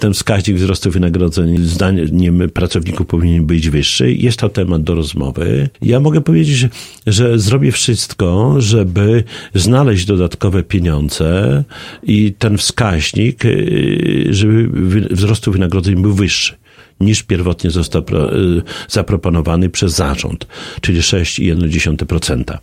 W piątek (01.02) podczas „Suwalskiego Magazynu Samorządowego” w Radiu 5 prezydent Suwałk zapewnił, że zrobi wszystko, aby znaleźć dodatkowe pieniądze na wyższe płace.